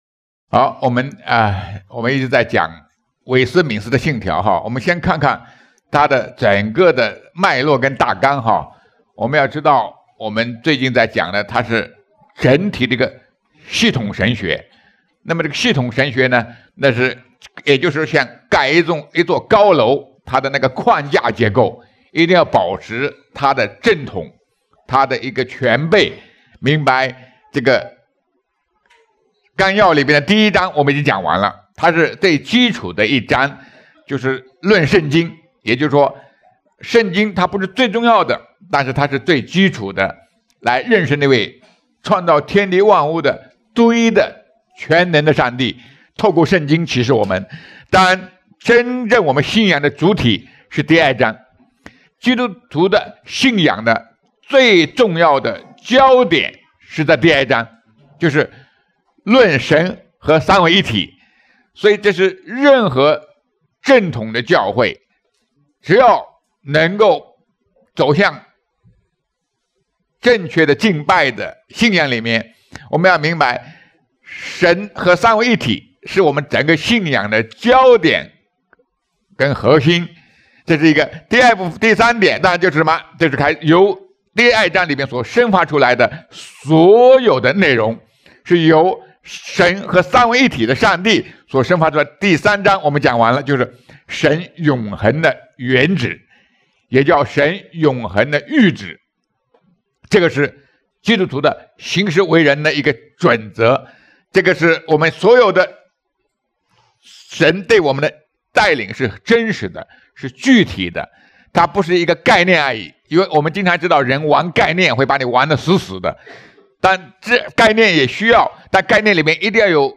圣经讲道